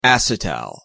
/ˈæsɪˌtæl(米国英語)/
• enPR: ăsʹĭtăl', IPA(key): /ˈæsɪˌtæl/